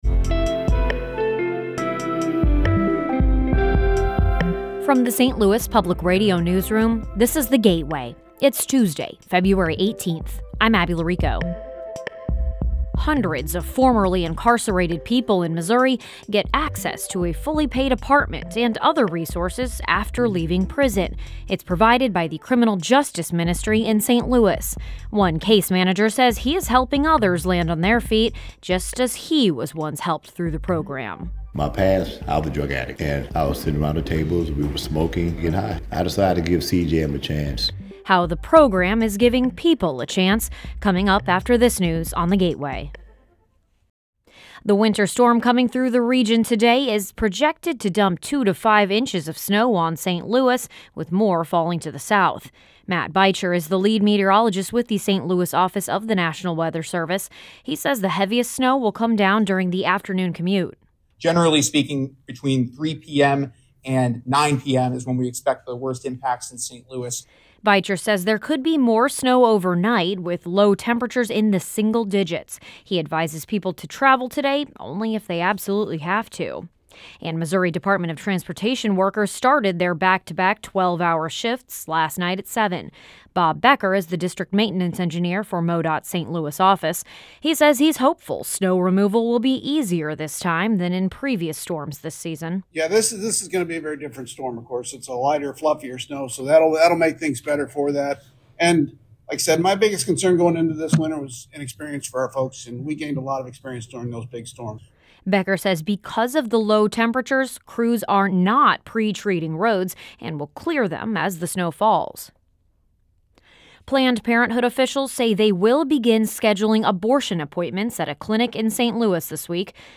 … continue reading 1447 episódios # St Louis # News # St Louis Public Radios